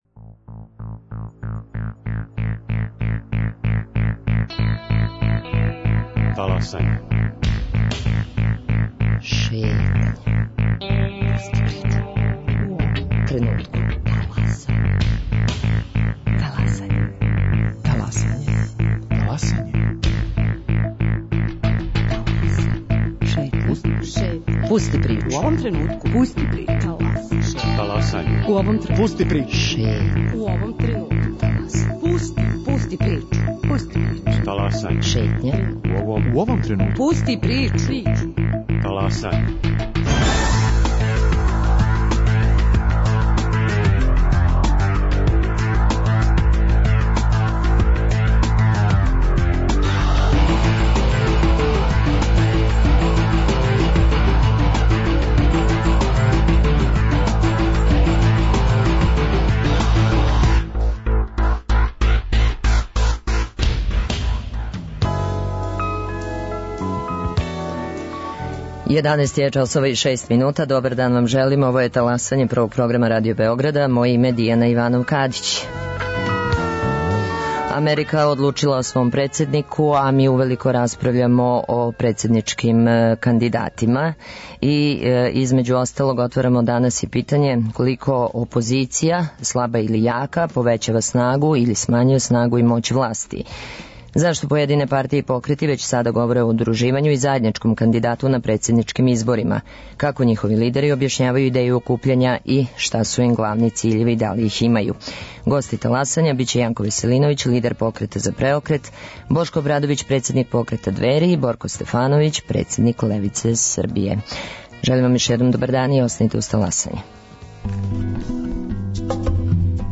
Гости Таласања Јанко Веселиновић - лидер Покрета за преокрет, Бошко Обрадовић- председник покрета Двери и Борко Стефановић - председник Левице Србије